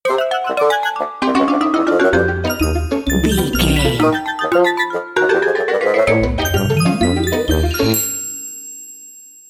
Short music, corporate logo or transition between images,
Epic / Action
Fast paced
In-crescendo
Uplifting
Ionian/Major
cheerful/happy
industrial
powerful
groovy
funky
synthesiser